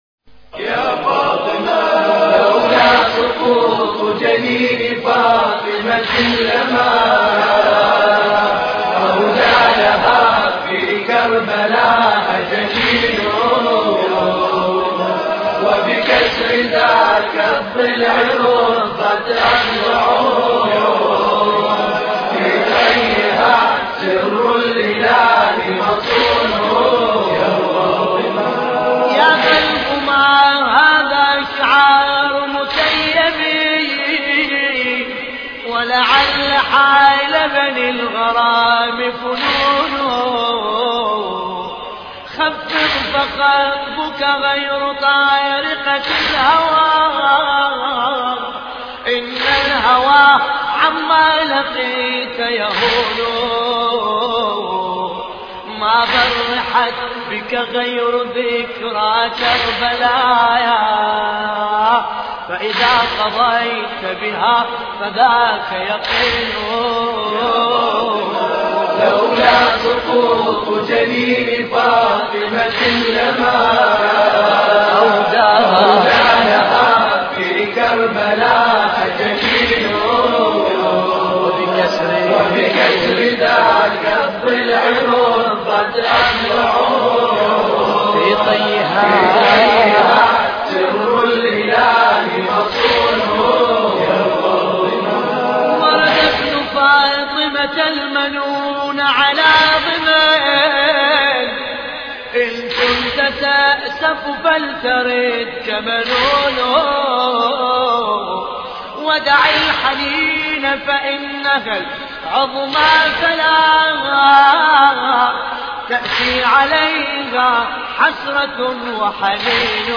مراثي فاطمة الزهراء (س)